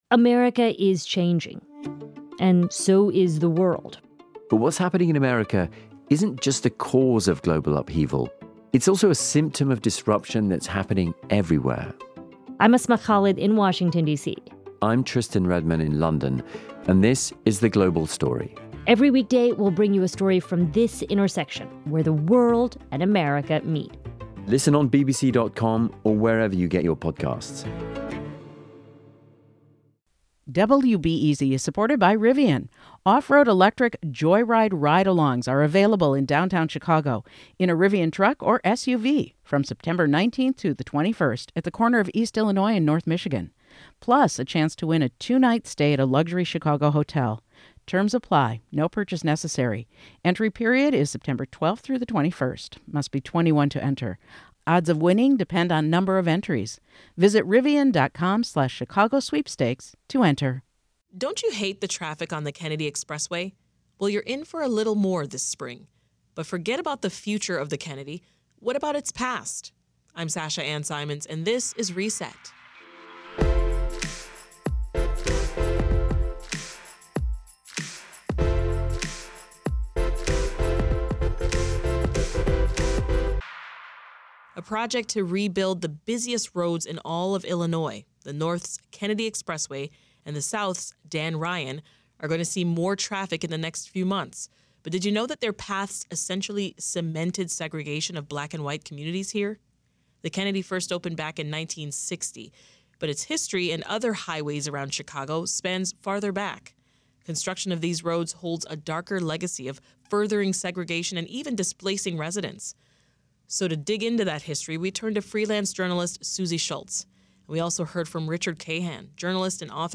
And the result was the entrenchment of segregation in Chicago. For a walk through this history, Reset turns to two reporters